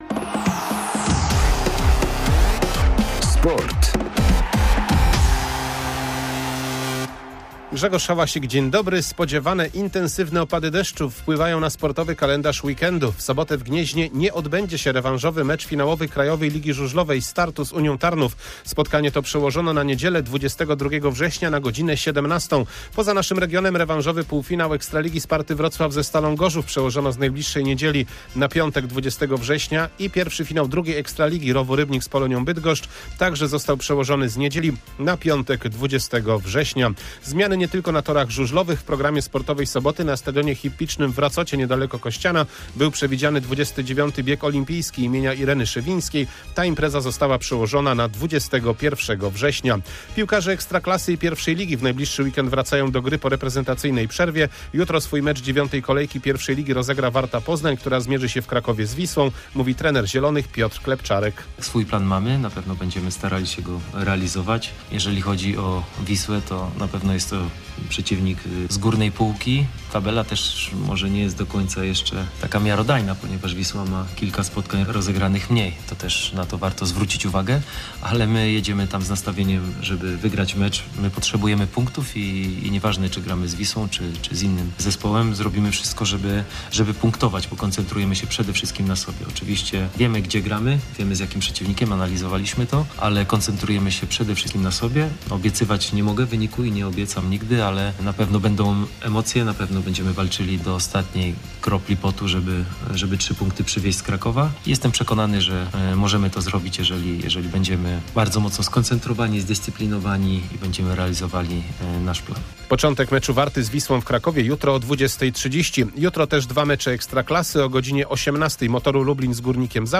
12.09.2024 SERWIS SPORTOWY GODZ. 19:05